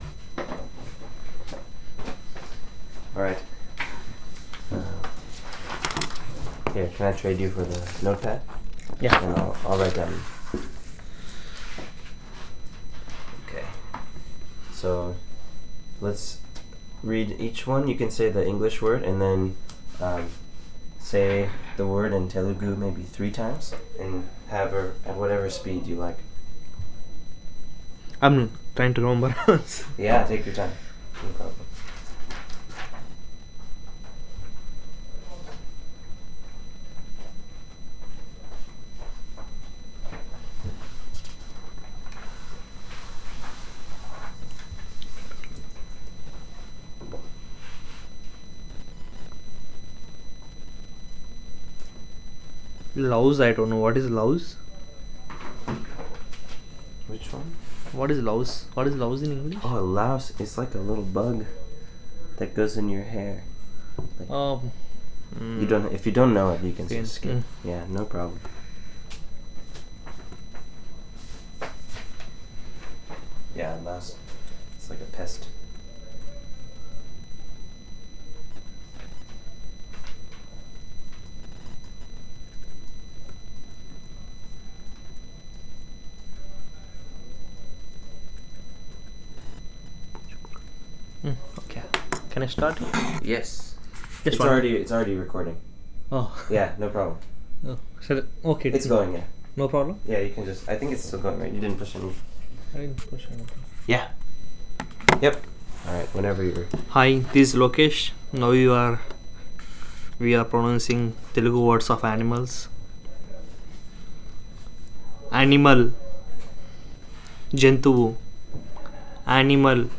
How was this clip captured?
digital wav file recorded at 44.1 kHz/24-bit with TASCAM DR-05 Andhra Pradesh, India; recording made in Honolulu, Hawaii